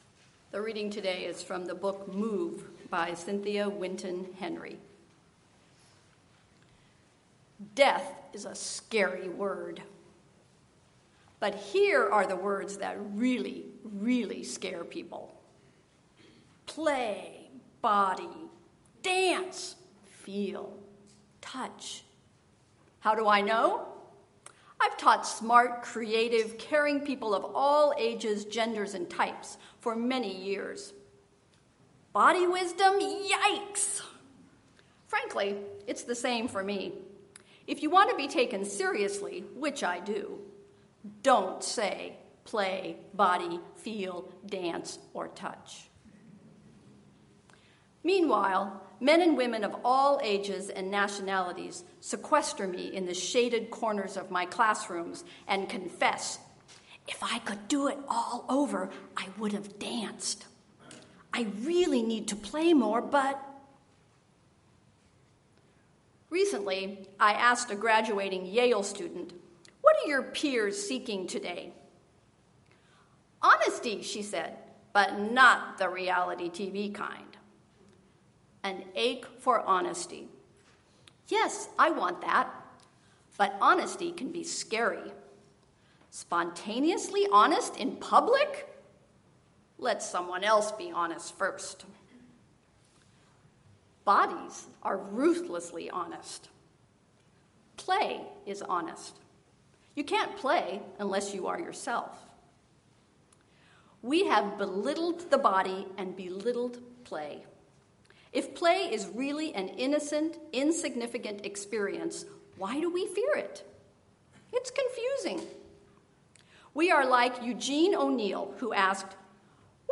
Sermon-What-the-Body-Wants.mp3